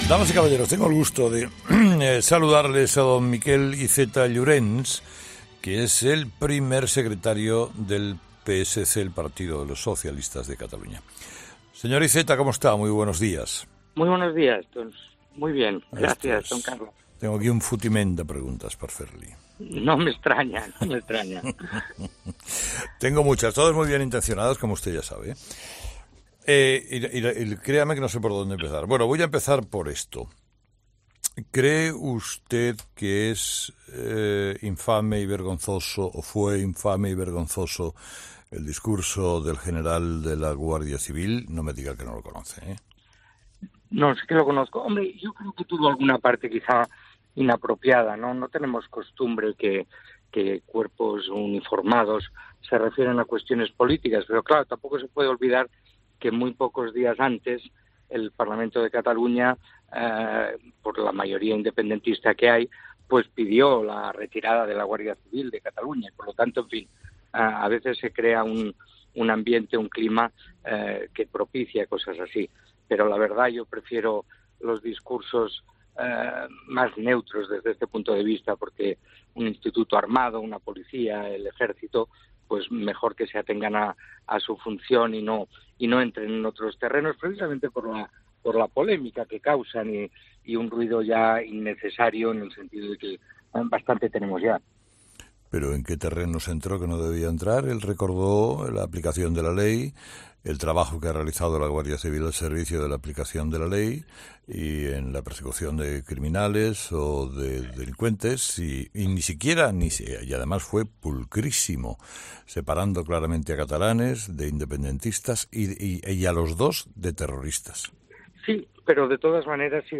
Miquel Iceta , el primer secretario del PSC, ha sido entrevistado este viernes en 'Herrera en COPE', donde se ha pronunciado sobre el discurso del jefe de la Guardia Civil en Cataluña, Pedro Garrido , durante la fiesta del Instituto Armado.